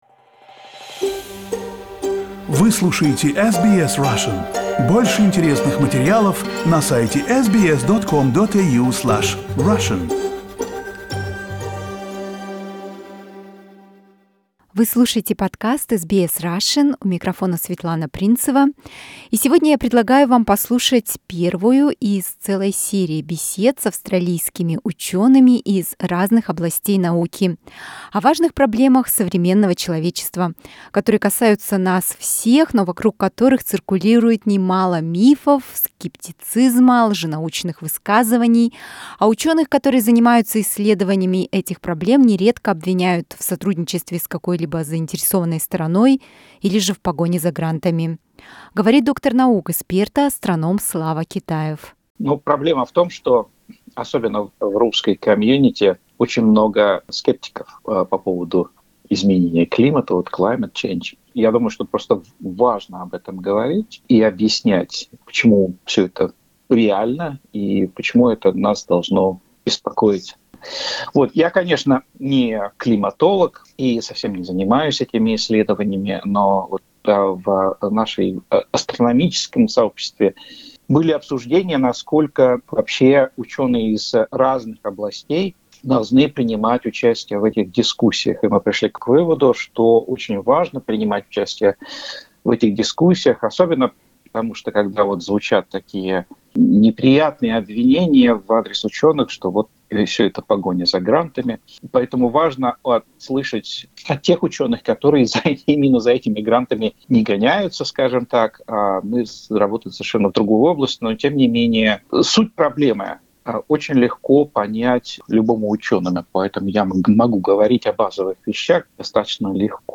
Беседы с австралийскими учеными: Изменение климата